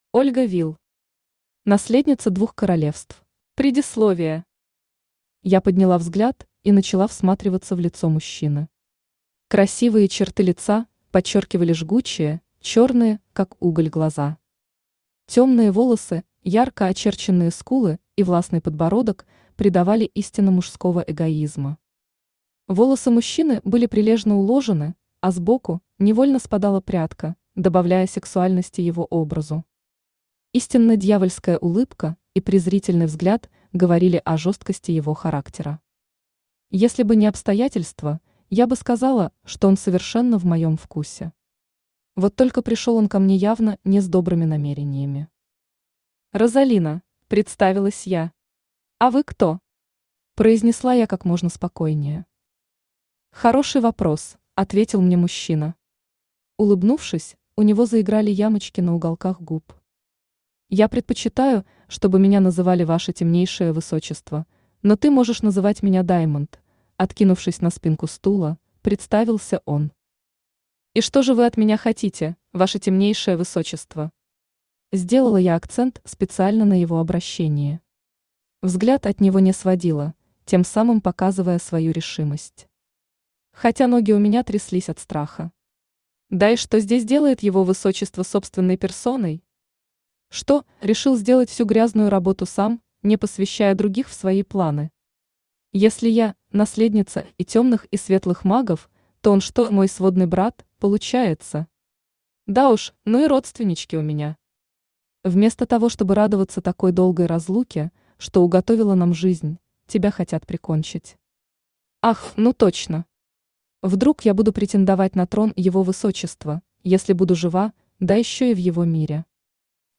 Аудиокнига Наследница двух Королевств | Библиотека аудиокниг
Aудиокнига Наследница двух Королевств Автор Ольга Вилл Читает аудиокнигу Авточтец ЛитРес.